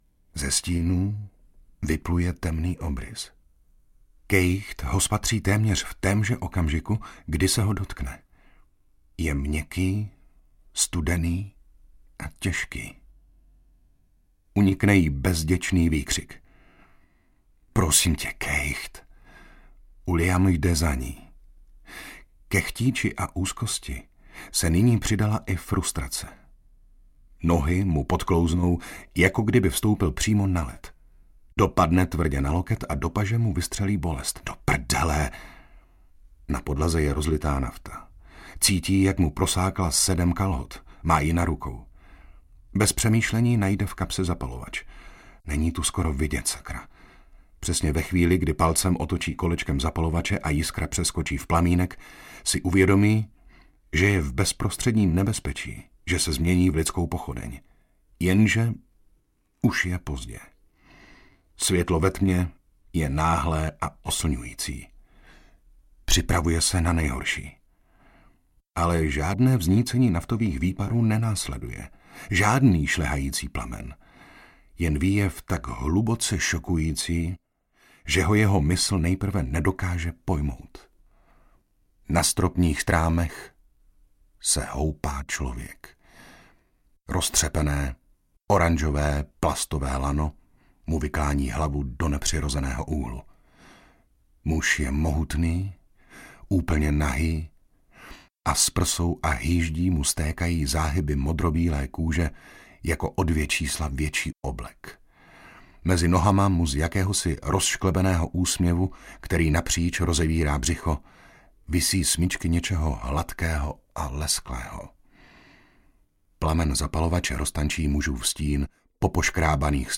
Skála audiokniha
Ukázka z knihy
• InterpretJiří Dvořák